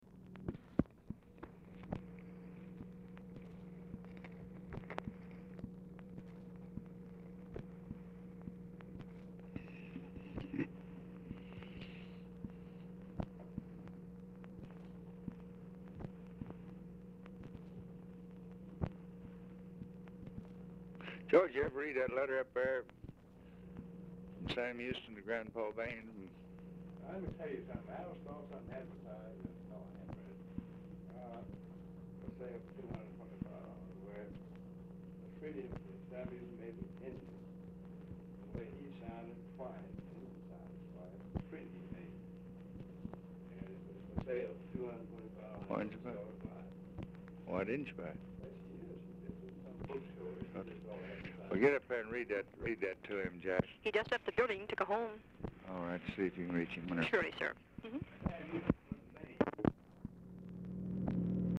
Telephone conversation # 523, sound recording, LBJ and TELEPHONE OPERATOR, 12/17/1963, time unknown | Discover LBJ
Format Dictation belt
Specific Item Type Telephone conversation